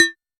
RDM_TapeA_SY1-Perc01.wav